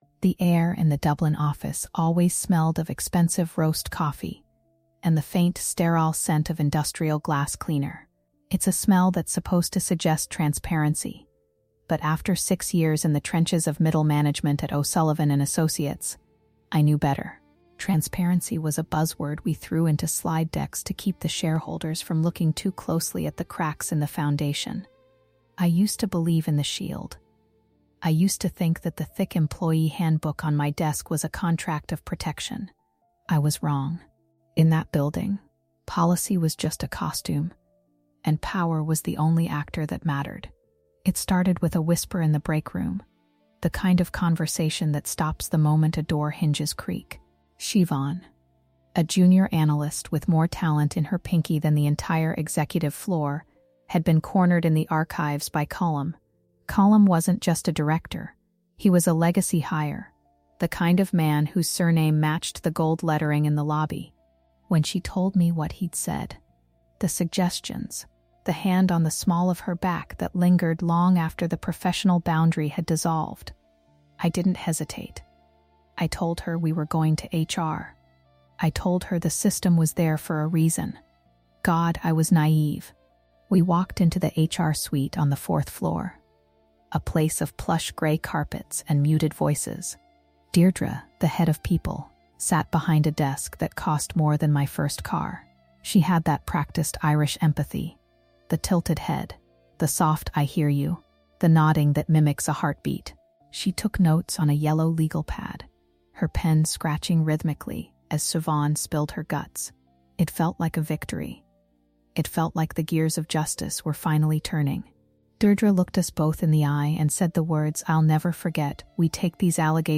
When a junior analyst reports a high-level Director for harassment, the HR department springs into action—not to find the truth, but to craft a narrative that shields the company’s legacy assets. Narrated by a first-person witness to the betrayal, this story exposes how human resources often functions as a legal barrier rather than a support system for employees.